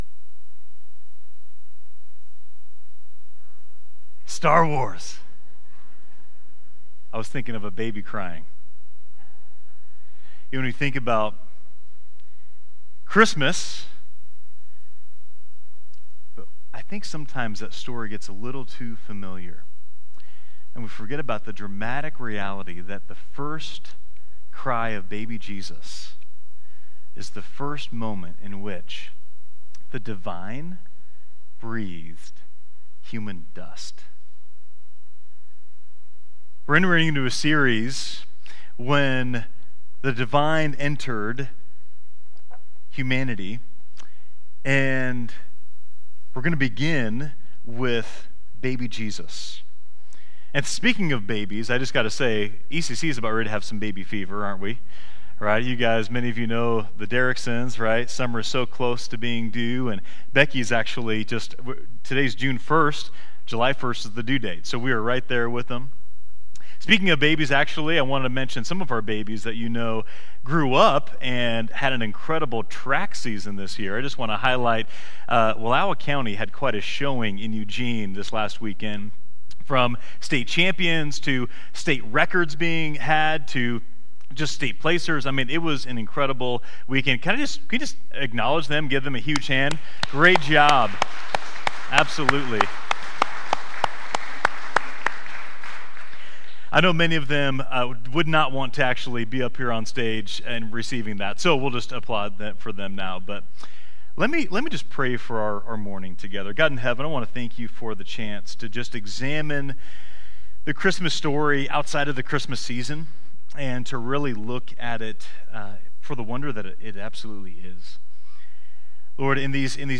Download Download Reference Luke 2, Matthew 1-2 Sermon Notes 1.